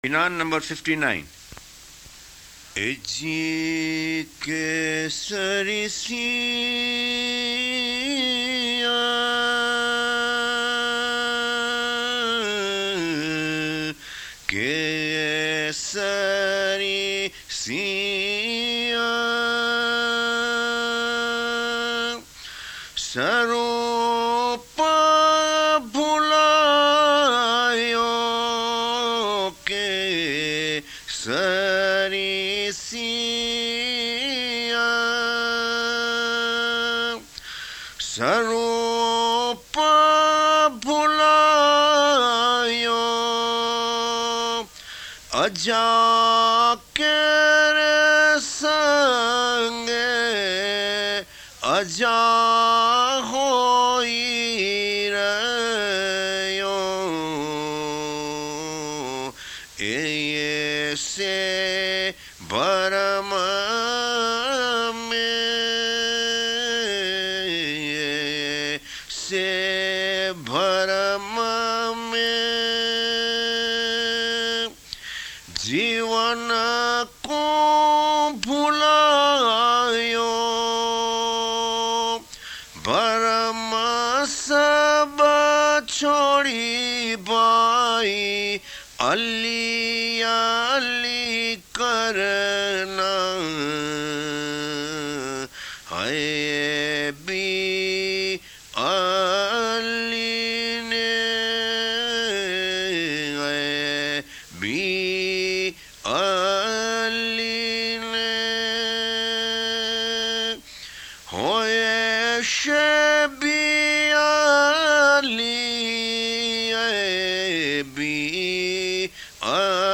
without music http